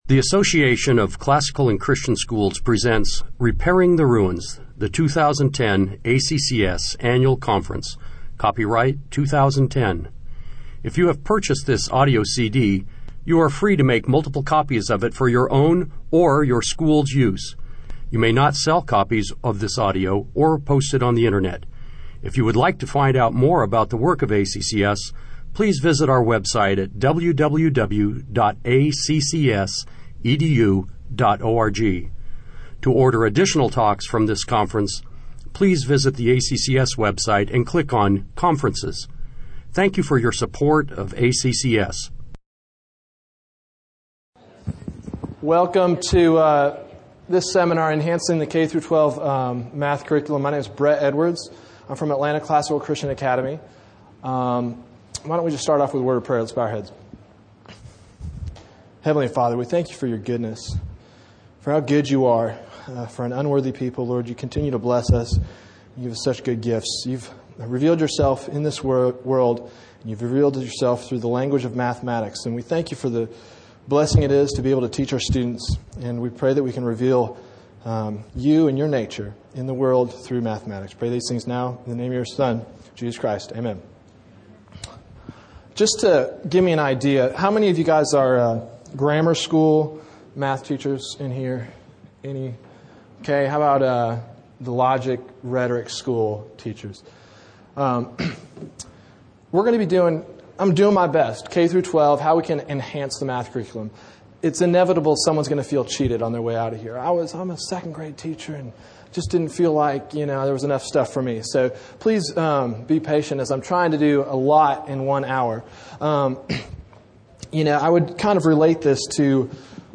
2010 Workshop Talk | 1:02:07 | All Grade Levels, Math
The Association of Classical & Christian Schools presents Repairing the Ruins, the ACCS annual conference, copyright ACCS.